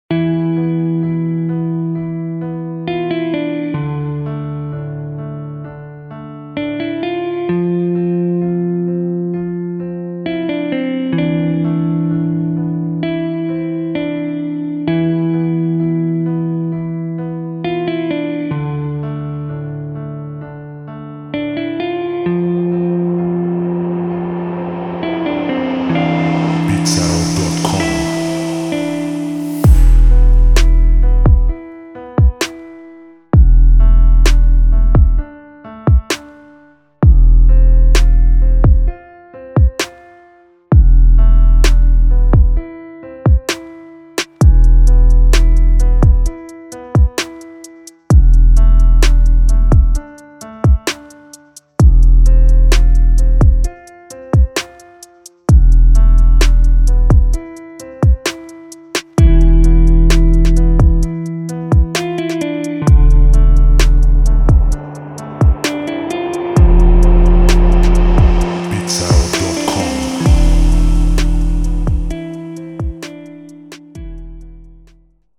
دسته و ژانر: پاپ
سبک و استایل: غمگین،عاشقانه
سرعت و تمپو: 130 BPM